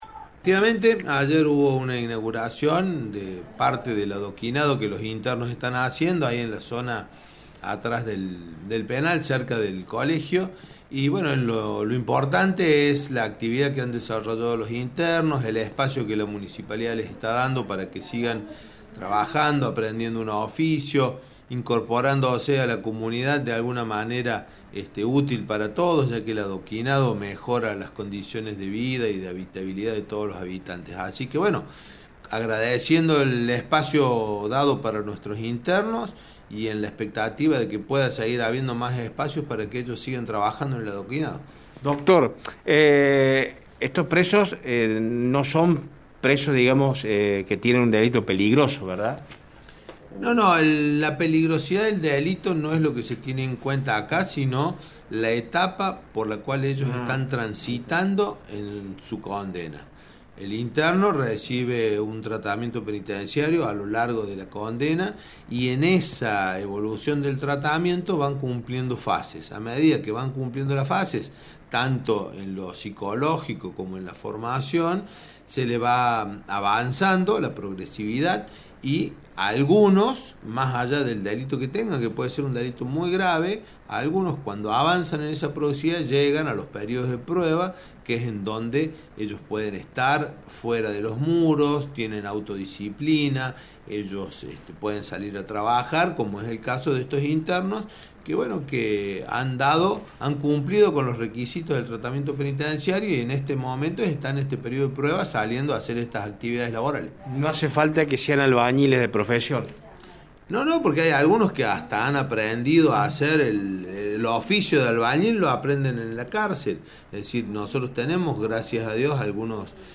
Arturo Ferreyra, juez de Ejecución Penal, habló con nuestro medio.